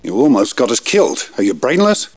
Listen to the sounds of Star Wars Episode one, with downloaded wav files for your listening pleasure.
--Qui-Gon Jinn--